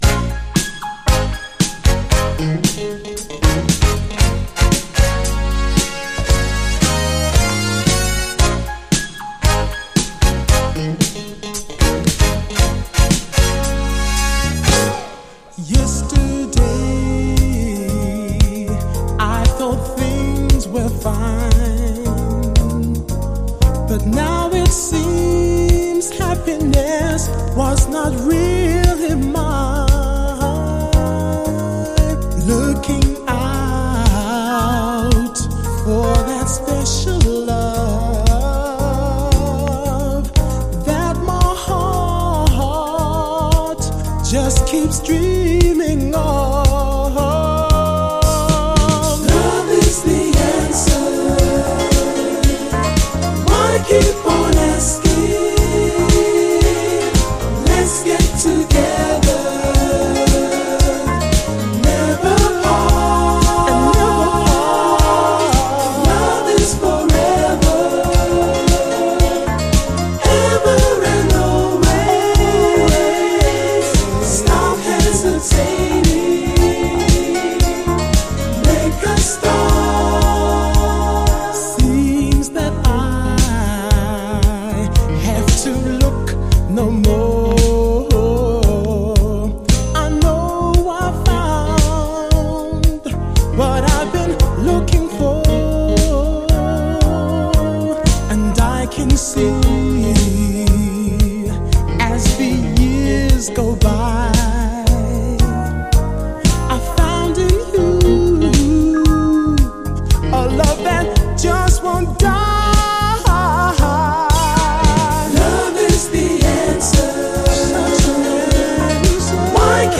UK産傑作エレガント・モダン・ソウルの12インチ！
抜群にキャッチーなイントロ、そしてエレピ＆ピアノが演出するエレガンス！